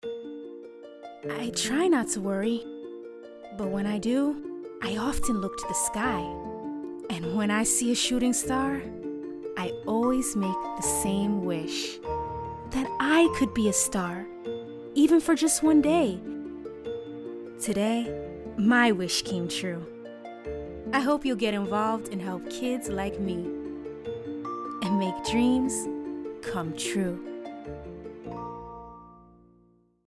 African American, Bilingual, Singer, Fun, Urban, Proper, Teen, Disney, Nickelodeon, Southern, Hip, Sassy, Sweet, Clear
Sprechprobe: Werbung (Muttersprache):
Naturally a Disney/Nickelodeon type of voice. HIGH ENERGY, young and FUN!!